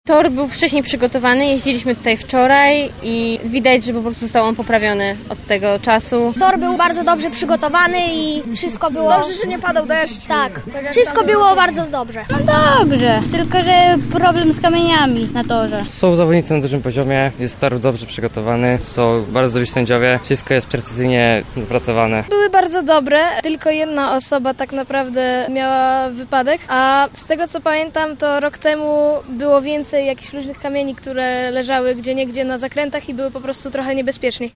Sonda.mp3